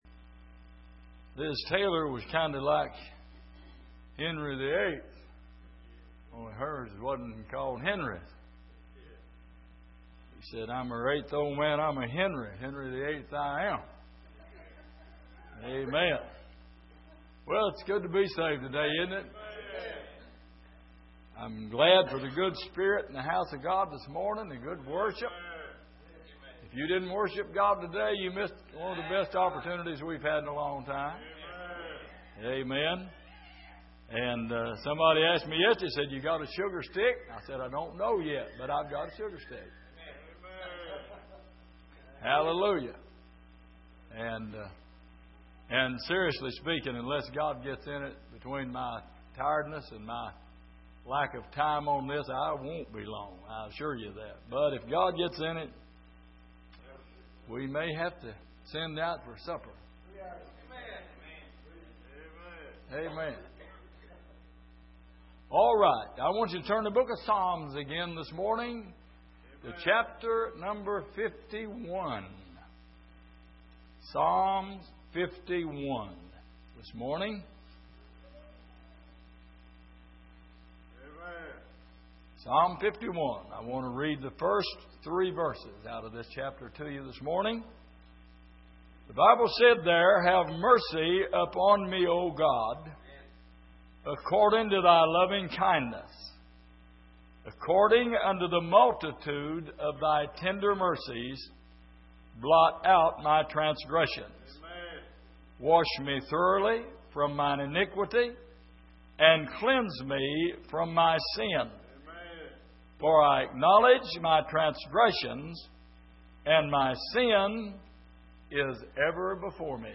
Passage: Psalm 51:1-3 Service: Sunday Morning